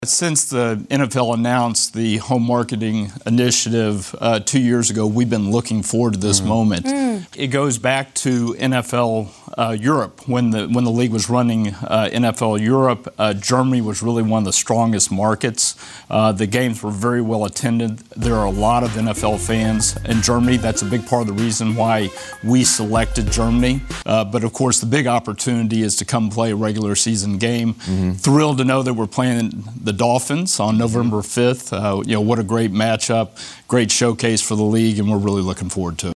Chiefs CEO and Chairman Clark Hunt talked about the game on the NFL Networks Good Morning Football.